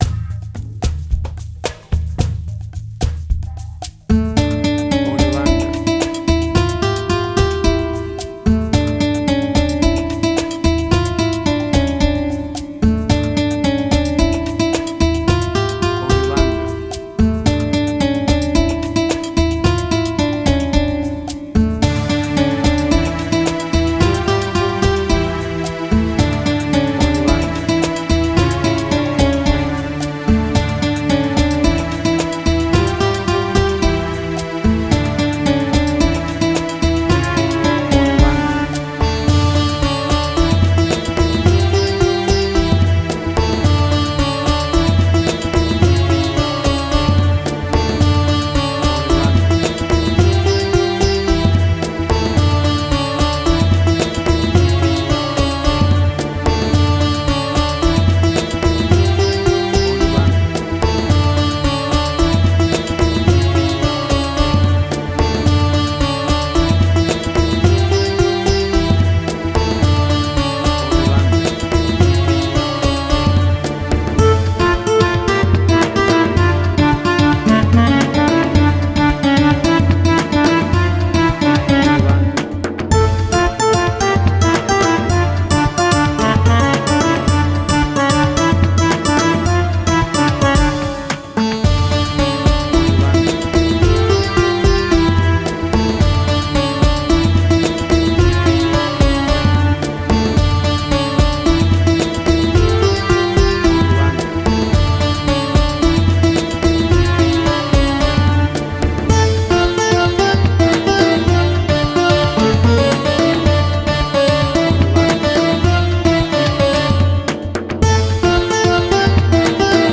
An exotic and mysterious Arabian night!
WAV Sample Rate: 16-Bit stereo, 44.1 kHz
Tempo (BPM): 110